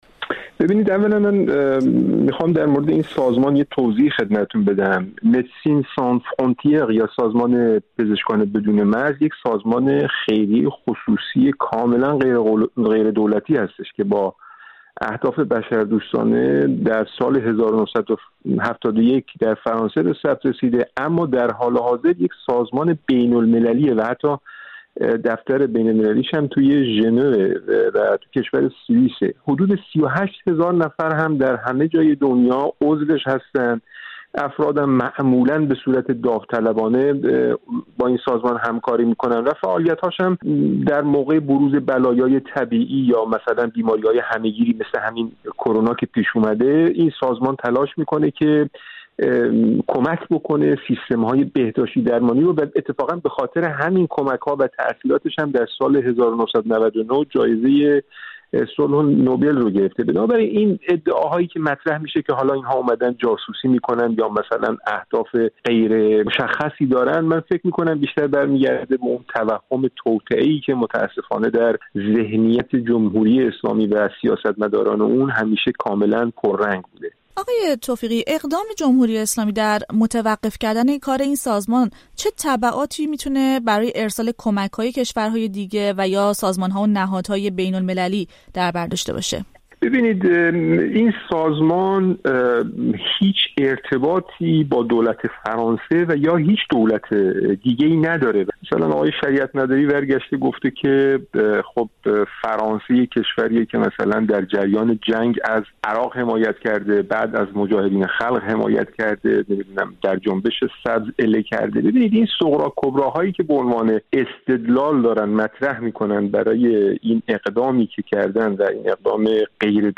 در گفت‌وگو با رادیو فردا در همین‌باره توضیح می‌دهد.